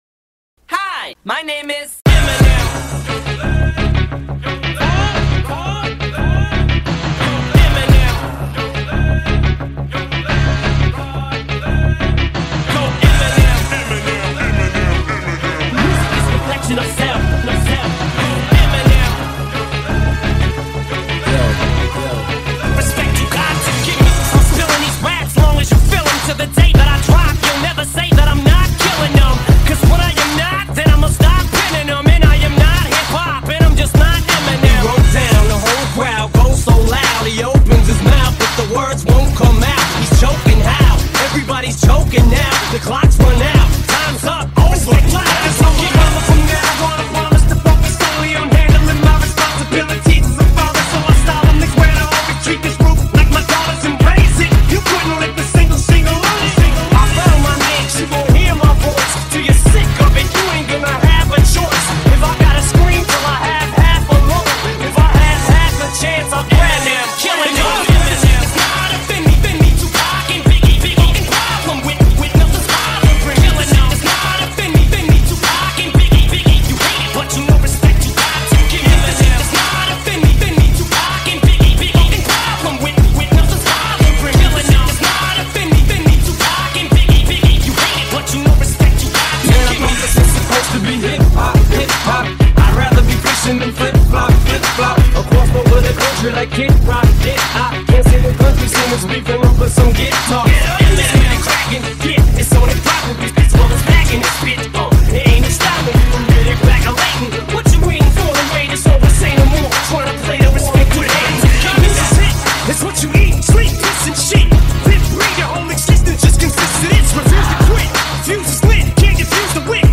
Жанр: Rap, Hip-Hop